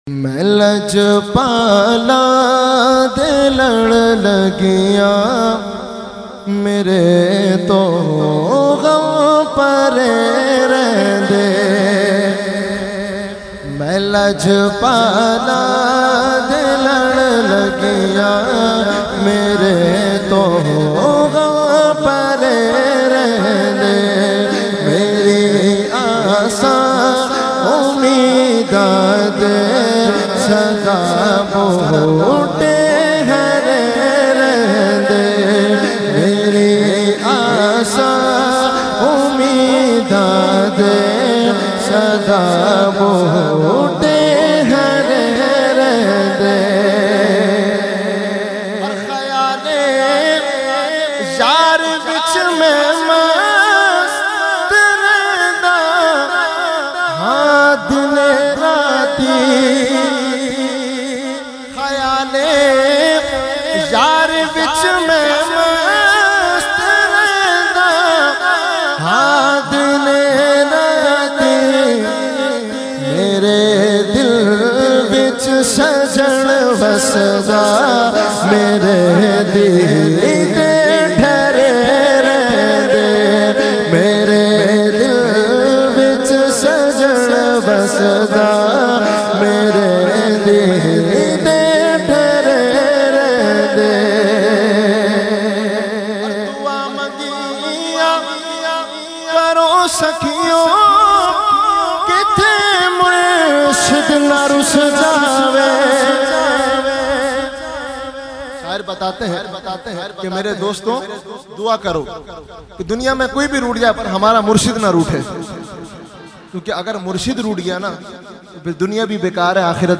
Category : Naat | Language : PunjabiEvent : Muharram 2020